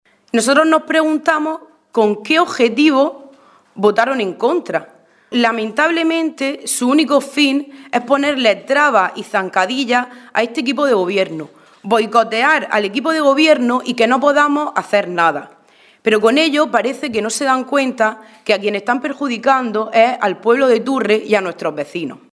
Rueda de prensa que ha ofrecido la alcaldesa de Turre, María Isabel López